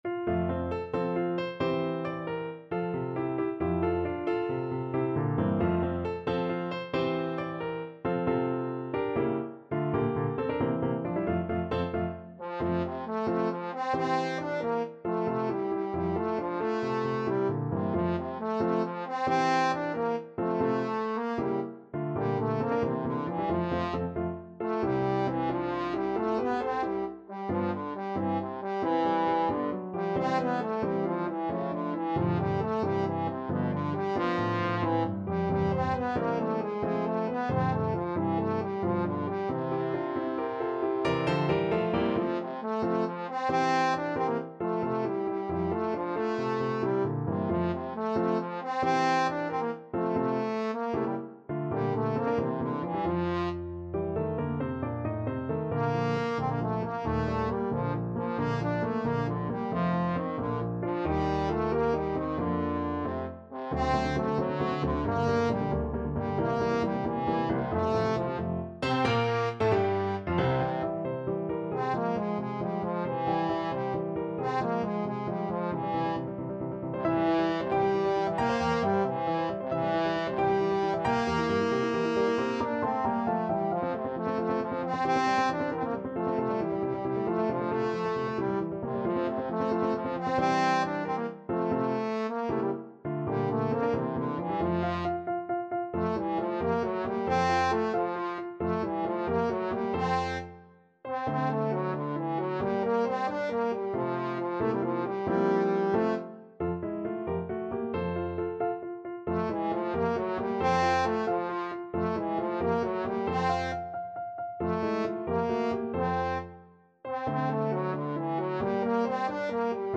Trombone
F major (Sounding Pitch) (View more F major Music for Trombone )
6/8 (View more 6/8 Music)
. = 90 Allegretto vivace
Classical (View more Classical Trombone Music)